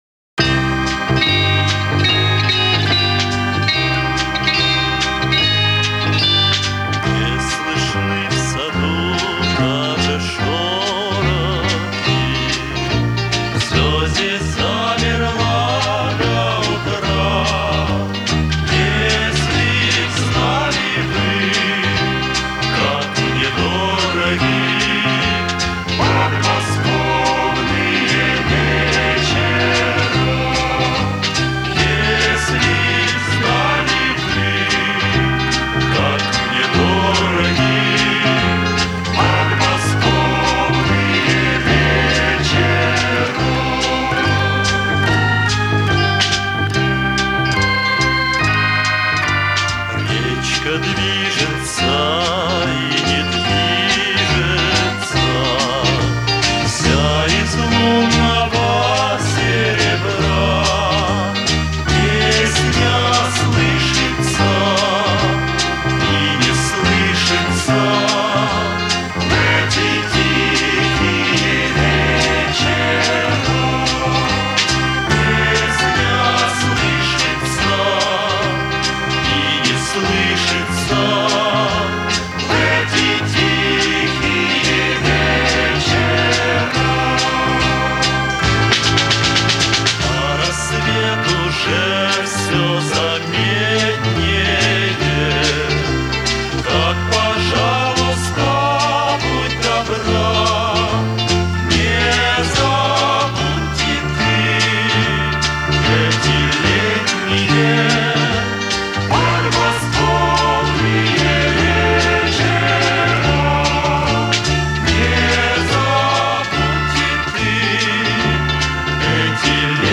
очень качественно звучит, наверняка, ремастирована